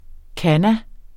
Udtale [ ˈkana ]